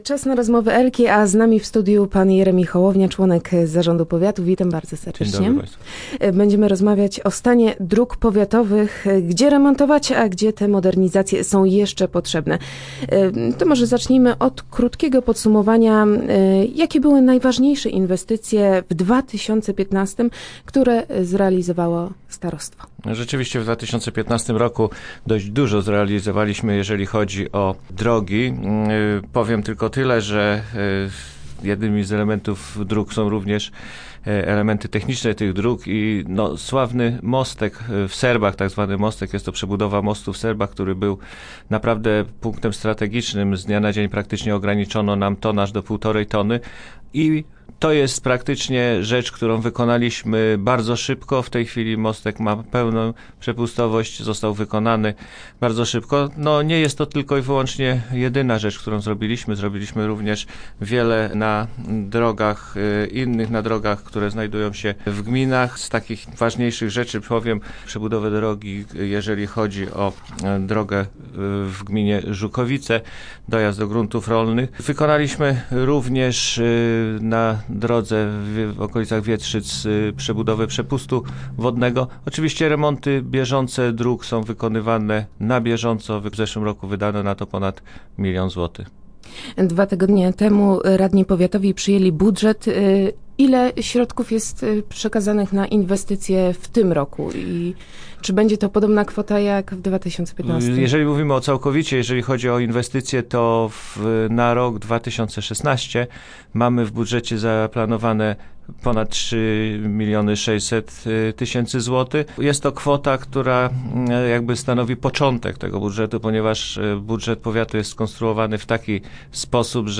Naszym gościem był Jeremi Hołownia, członek zarządu powiatu.